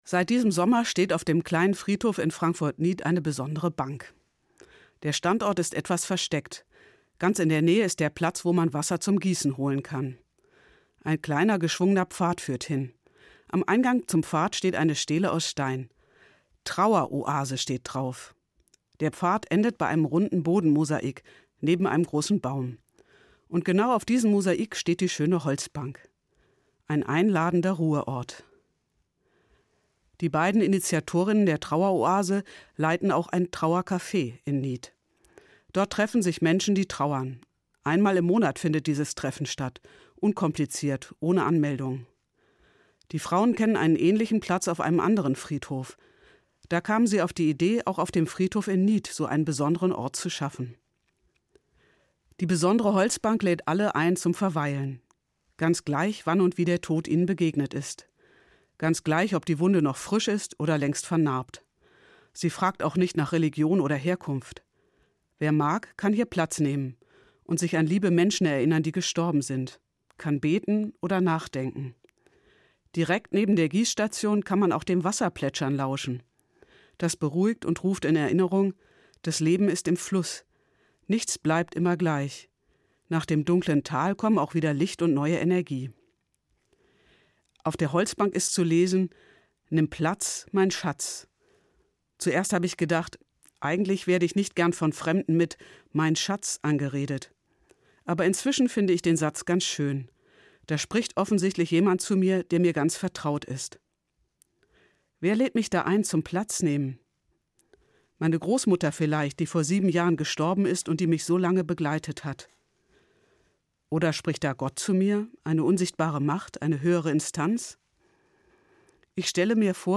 Evangelische Pfarrerin, Frankfurt